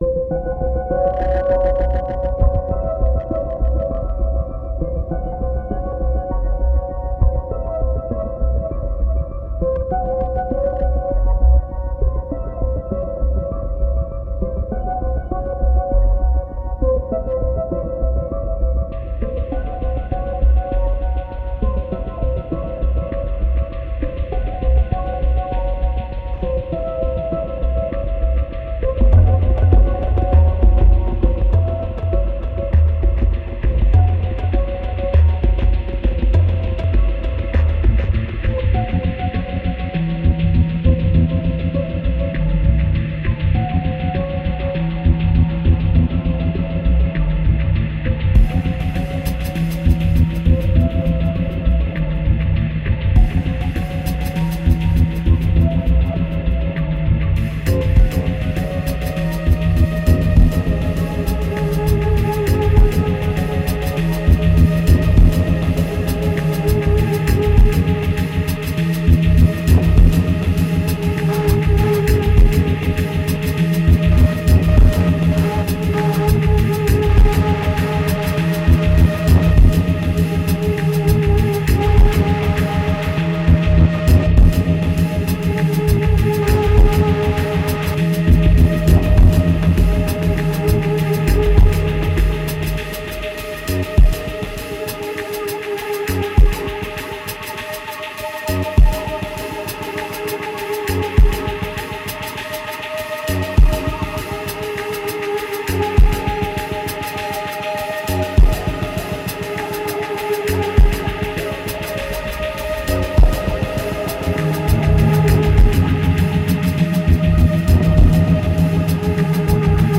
2137📈 - 36%🤔 - 100BPM🔊 - 2011-04-10📅 - -15🌟